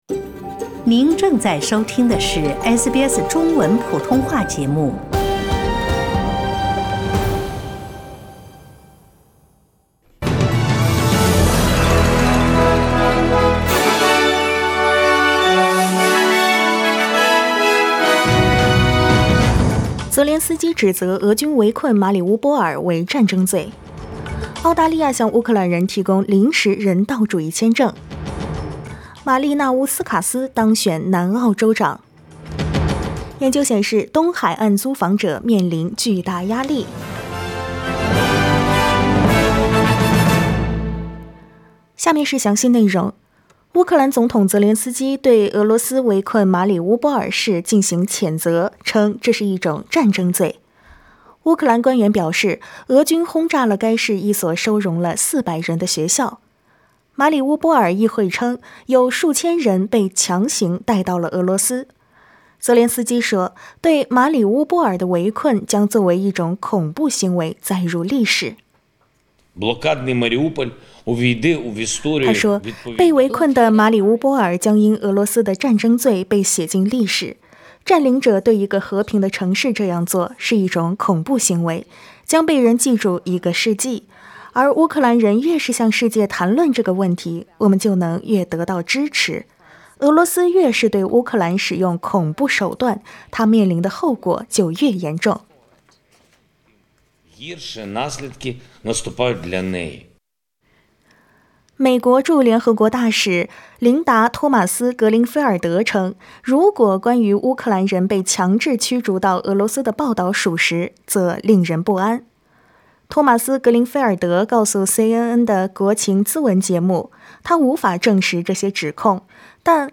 SBS早新闻（3月21日）
SBS Mandarin morning news Source: Getty Images